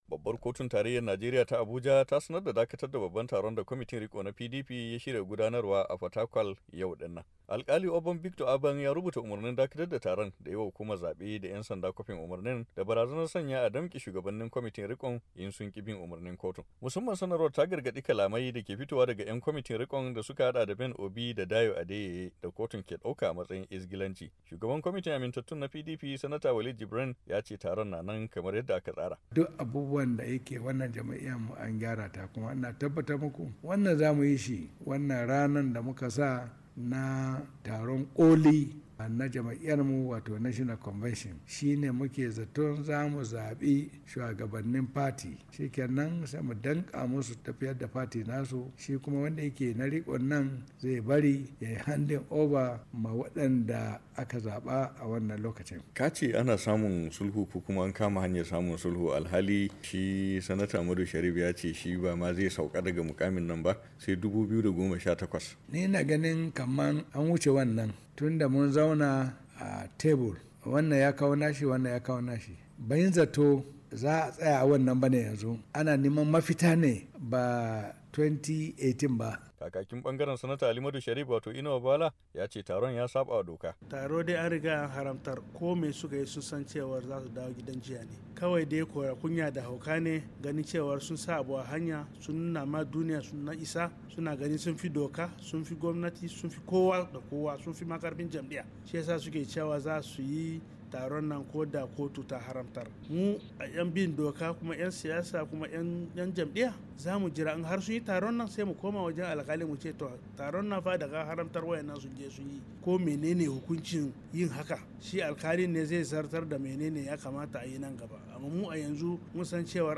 Saurari rahotan